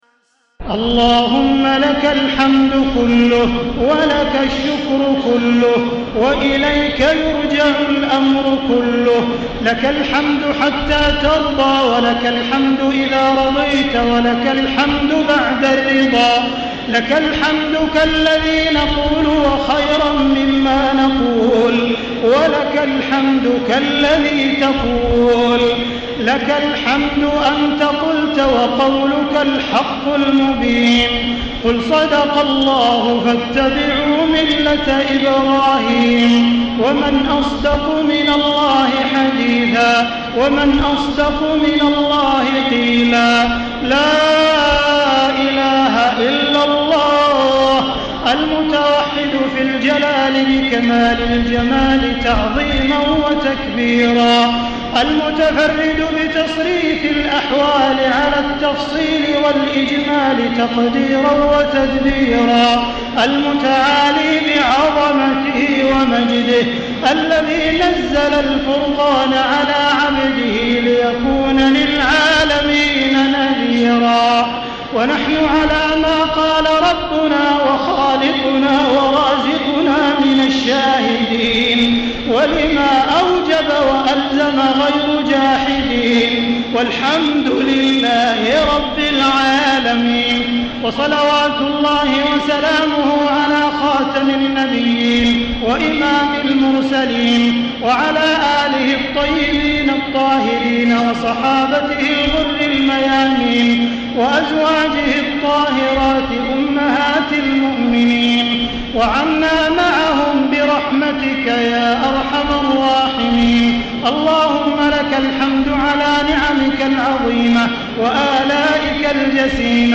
دعاء ختم القرآن 1435
المكان: المسجد الحرام الشيخ: معالي الشيخ أ.د. عبدالرحمن بن عبدالعزيز السديس معالي الشيخ أ.د. عبدالرحمن بن عبدالعزيز السديس دعاء ختم القرآن 1435